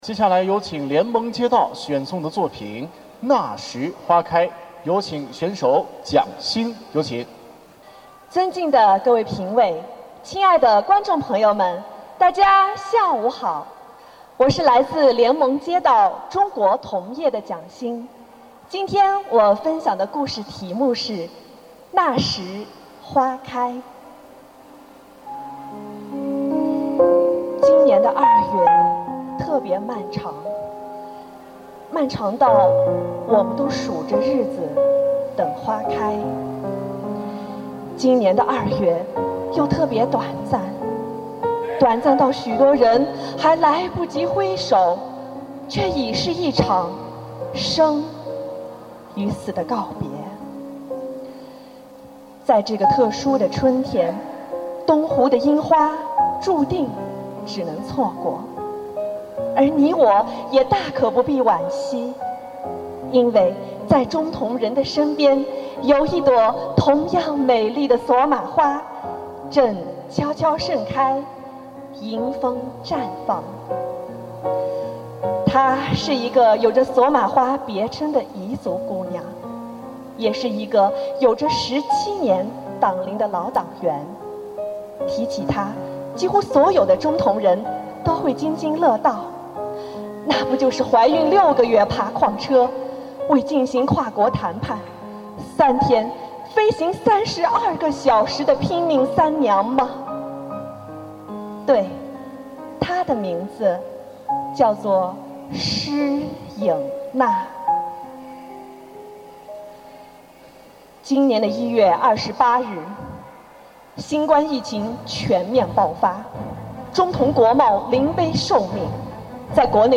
为把每一个“好人故事”讲给所有人听，我们专门为每一个参赛选手录制了音频，每天我馆都将在微信平台为您展播一位优秀选手带来的好人故事。
2020-08-28 17:30 现场录音